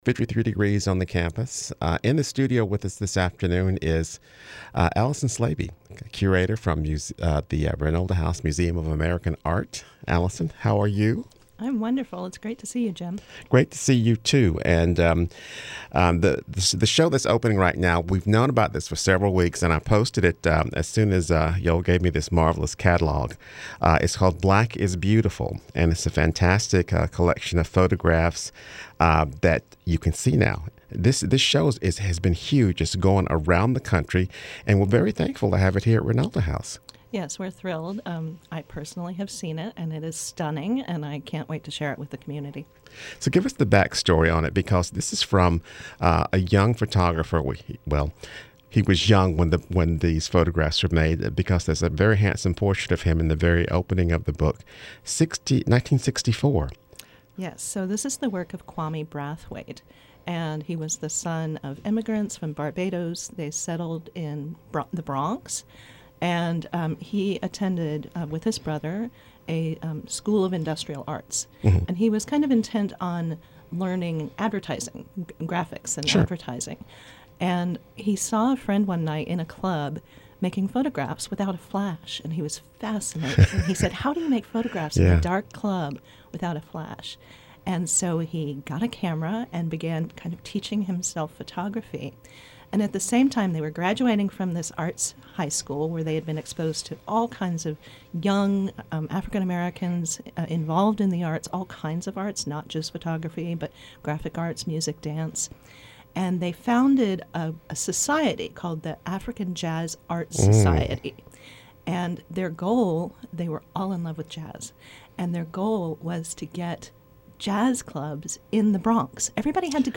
A fifteen (15) minute interview with representatives of local cultural arts and non-profit organizations throughout the Triad.